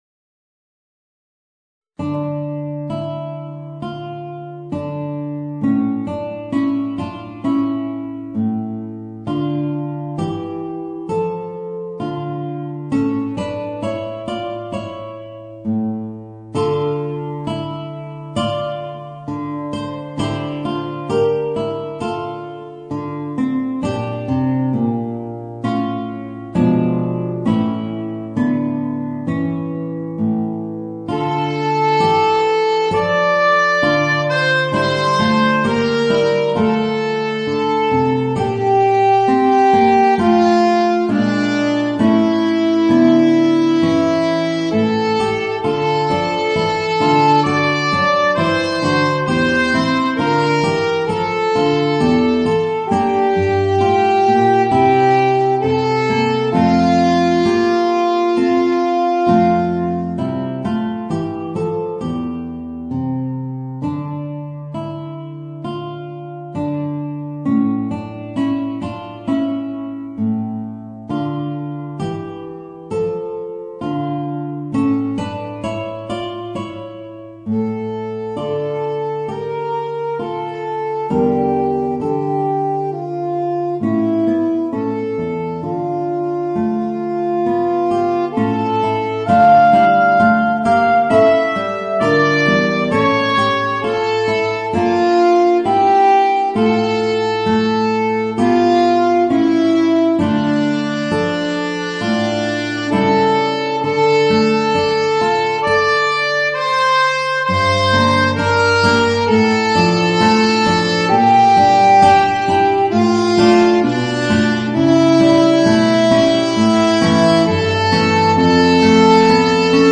Voicing: Alto Saxophone and Guitar